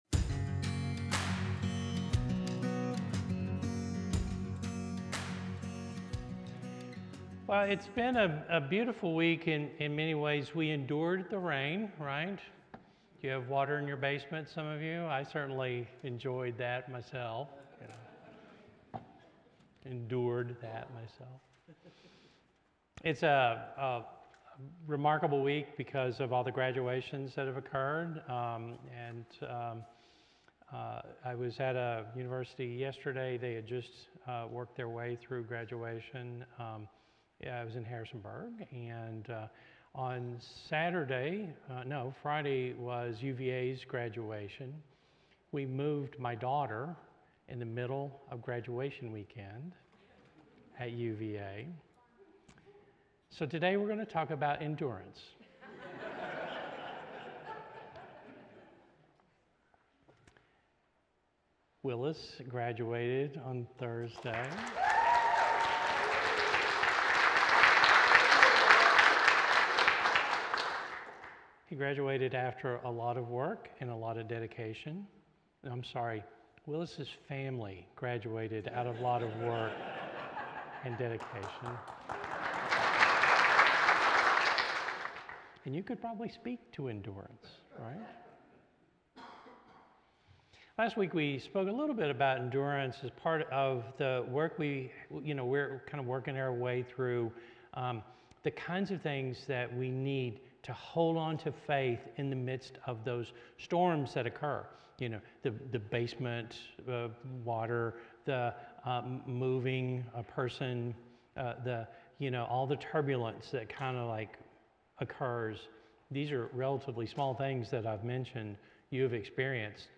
He emphasizes that life's troubles produce endurance, which in turn builds character and hope. The sermon encourages believers to run the race of faith with patience and courage, reminding them that Jesus and a "great cloud of witnesses" are cheering them on.